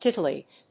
音節tit・il・late 発音記号・読み方
/tíṭəlèɪt(米国英語), ˈtɪtʌˌleɪt(英国英語)/